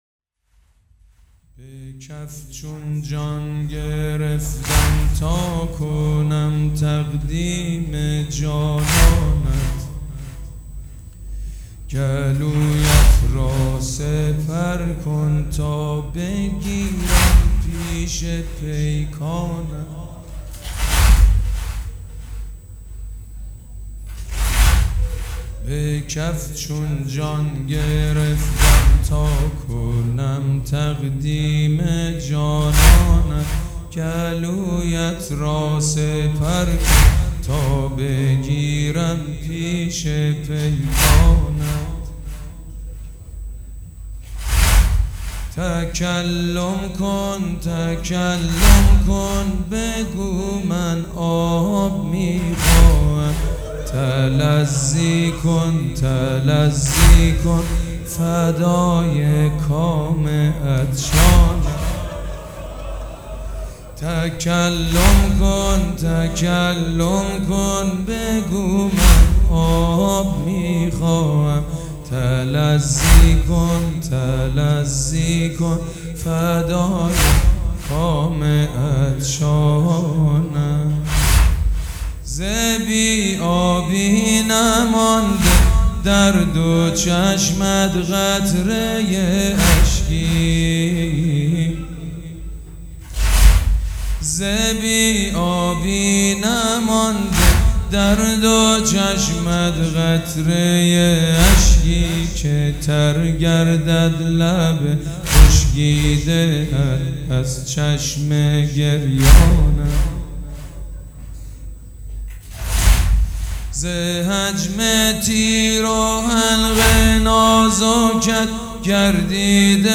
سید مجید بنی فاطمه